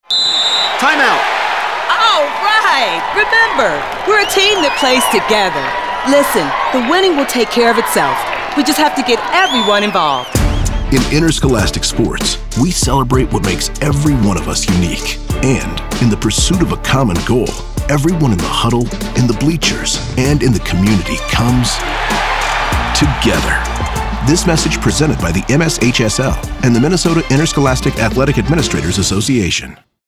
Public Service Announcements